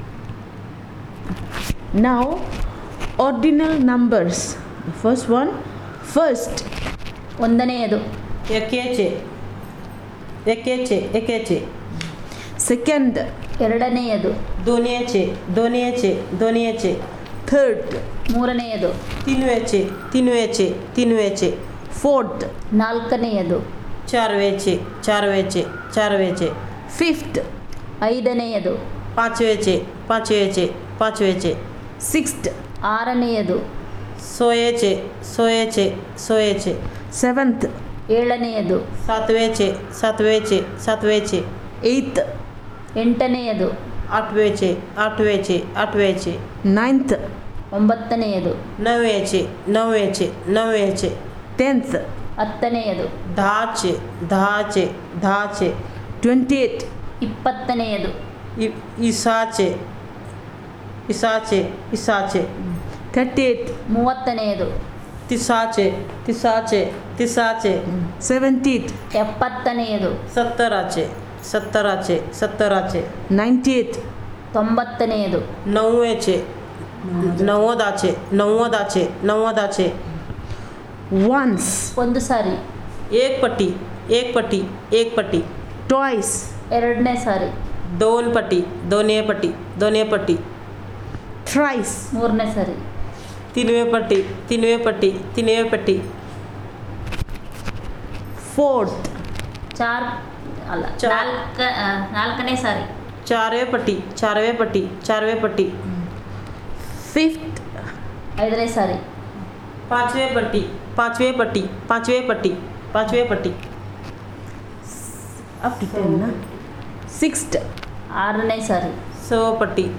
NotesThis is an elicitation of words about ordinal numbers using the SPPEL Language Documentation Handbook.